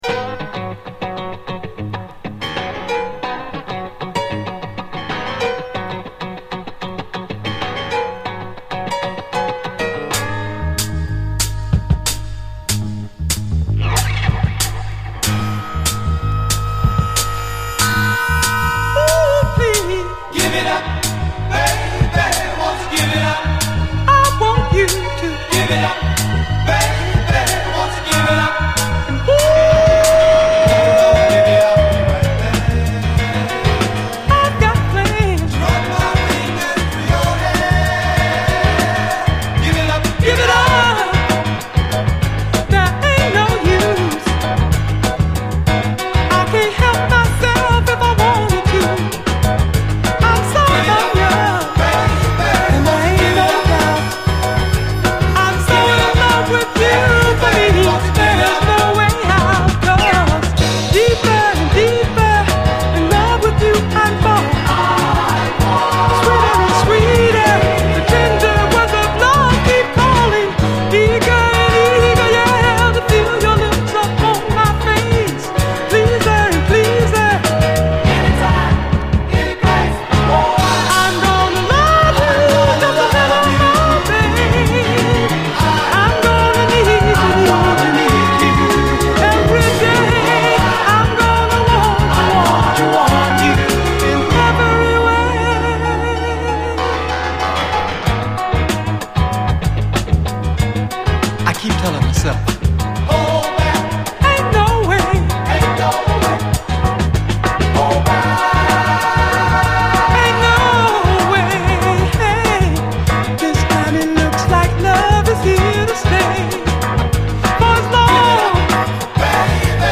インストながらも尻上がりに熱を帯びるブラス＆ピアノ・ワークに盛り上がらざるをえない傑作フロア・チューン！